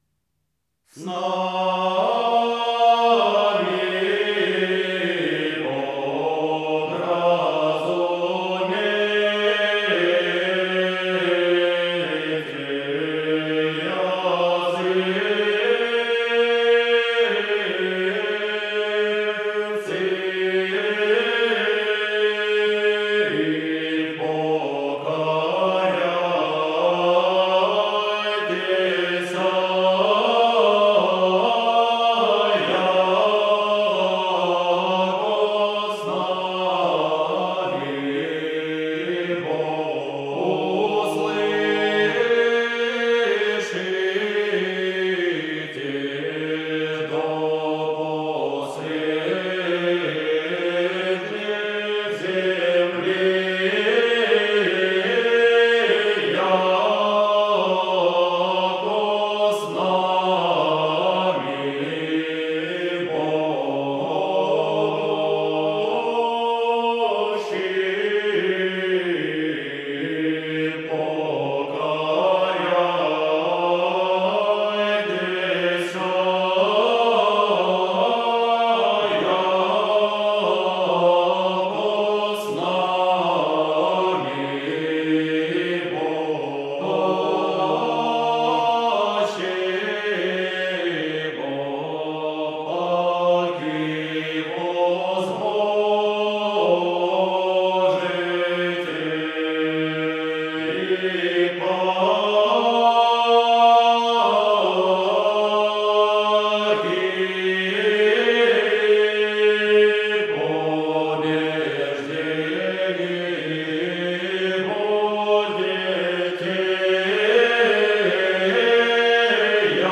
Рождественские песнопения и колядки